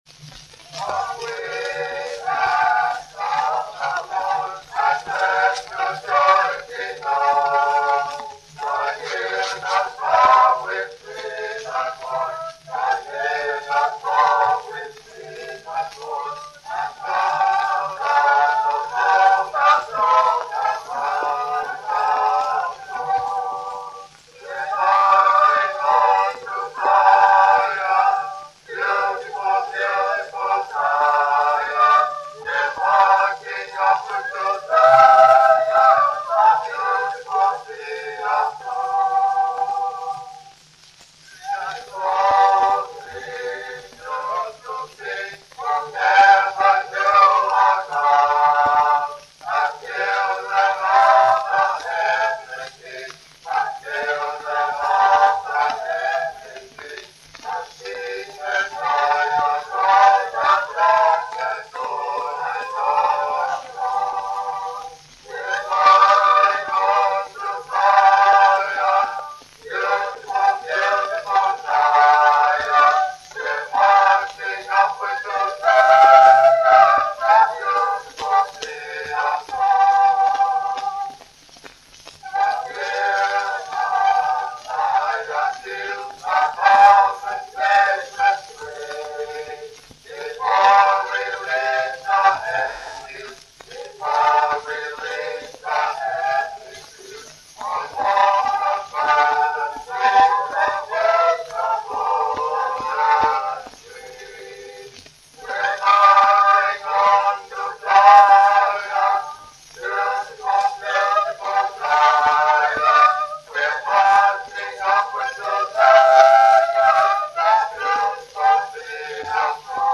The video is a repetitive chant or song asking the Lord to let His joy be known.